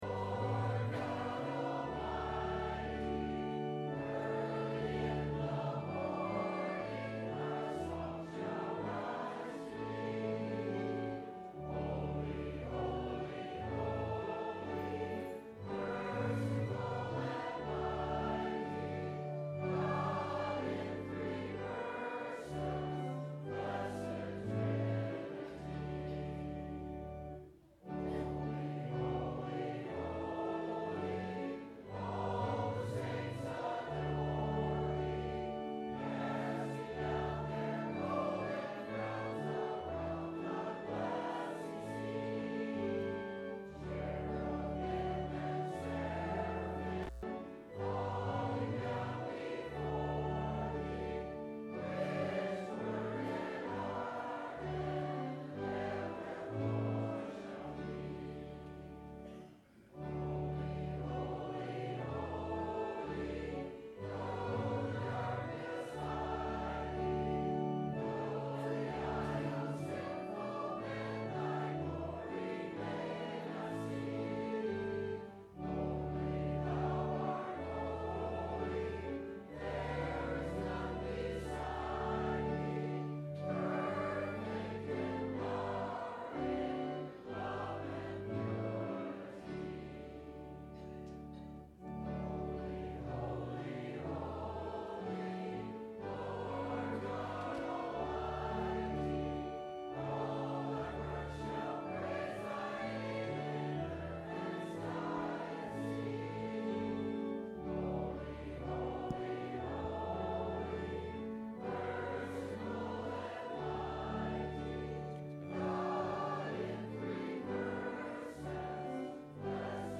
Certainty in Life and in Death thru Jesus – Sermon – Good Friday – March 29 2013